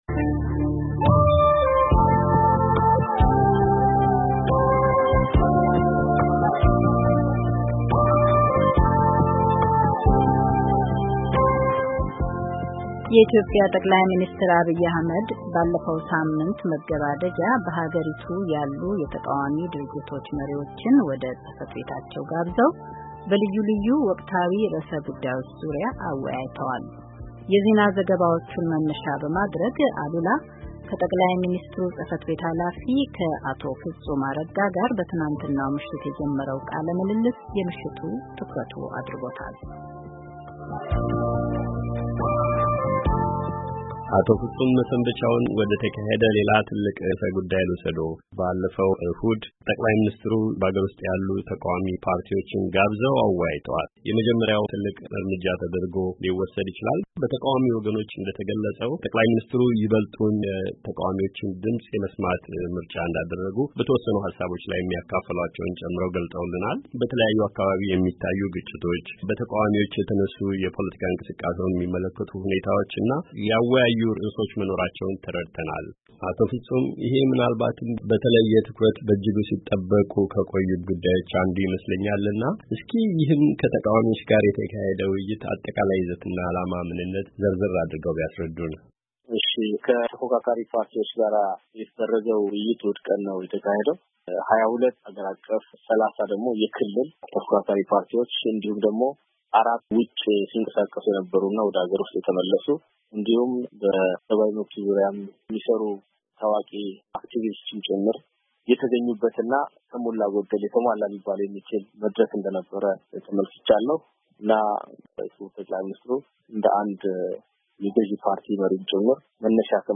ቆይታ ከጠቅላይ ሚንስትር ጽ/ቤት ኃላፊ አቶ ፍጹም አረጋ ጋር -ክፍል ሁለት